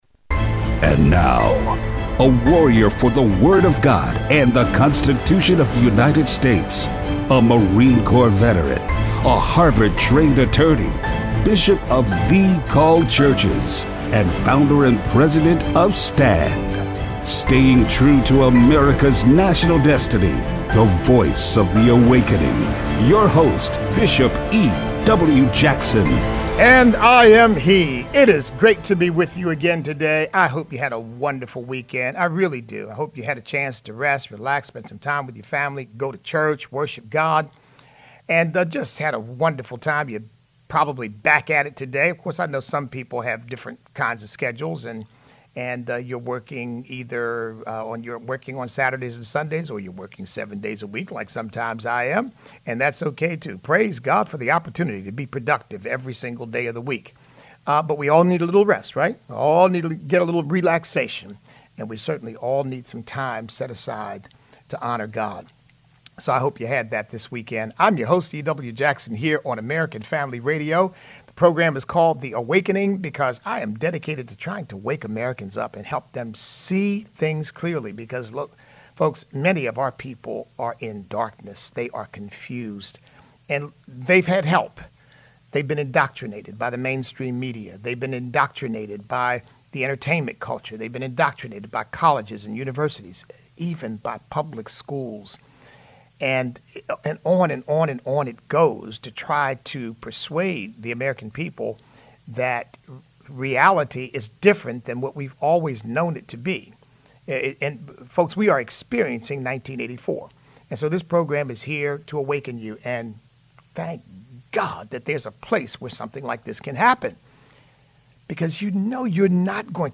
The NFL and Colin Kaepernick. Listener call in.